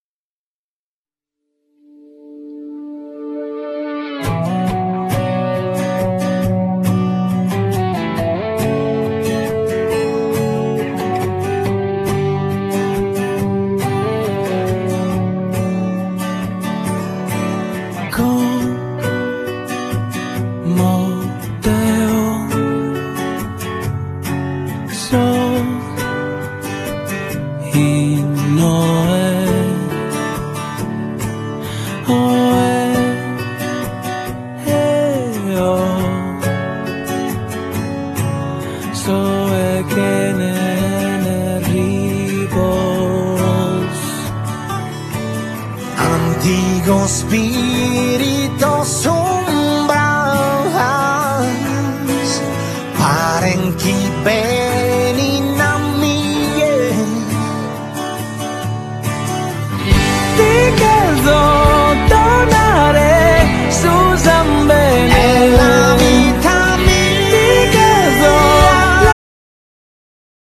Genere : Pop
brano intenso e coinvolgente